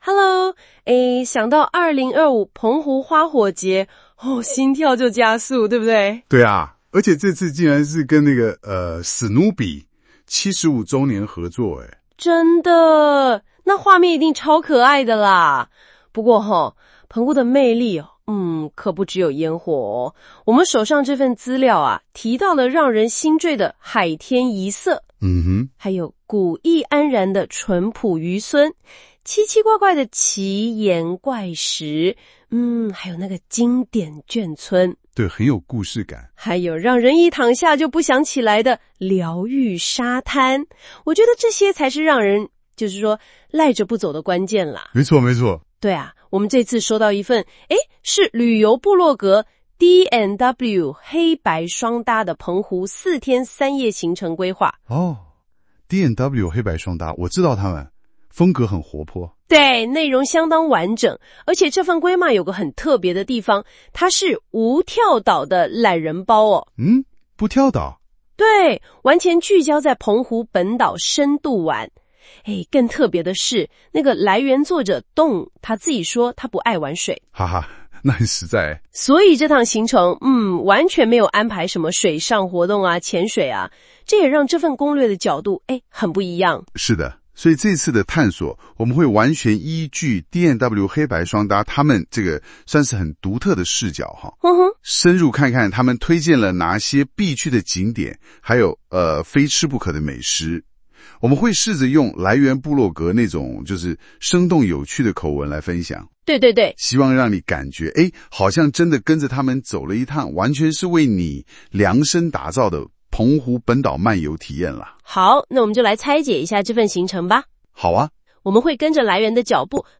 我們請兩位主持人專業講解，介紹D&W黑白雙搭澎湖懶人包各景點，可搭配文章圖片一起服用哦